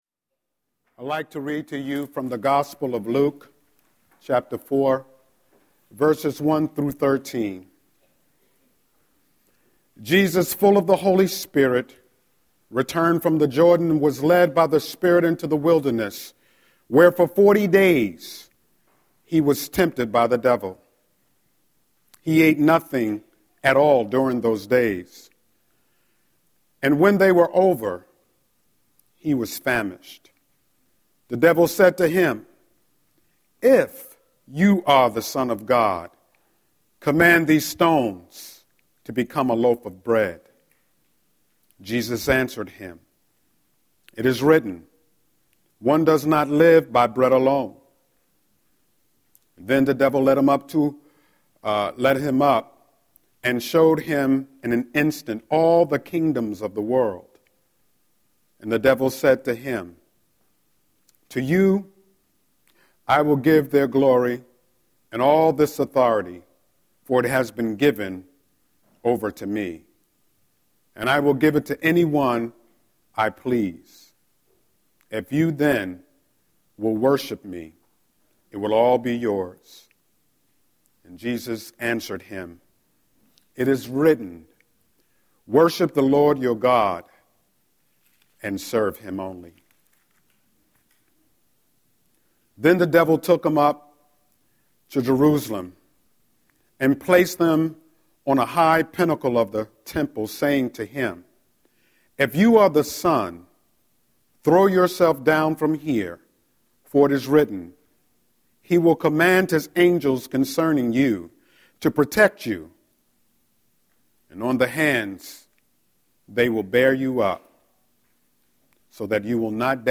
02-14-Scripture-and-Sermon.mp3